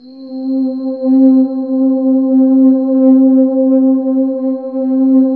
Index of /90_sSampleCDs/Sound & Vision - Gigapack I CD 2 (Roland)/PAD_SYNTH-PADS 2/PAD_Synth-Pads 5
PAD      09L.wav